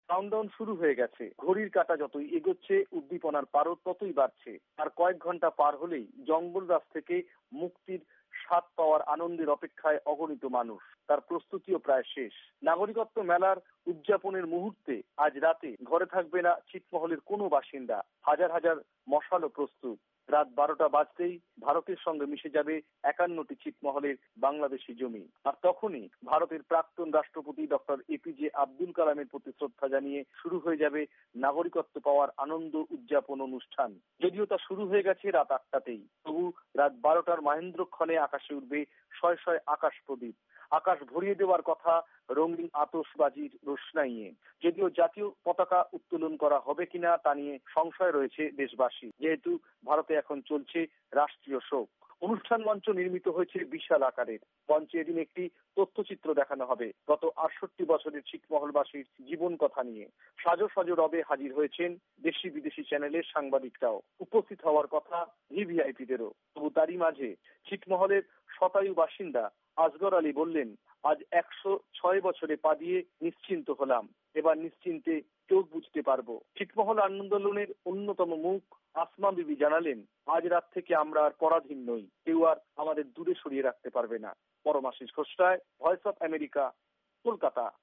ছিঁটমহল বিনিময় বাস্তবায়ন : ভারত থেকে প্রতিবেদন